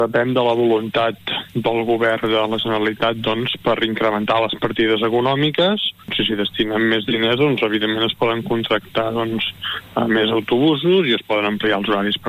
Buch assenyala que dependrà de la voluntat política del Govern de la Generalitat posar-hi solució: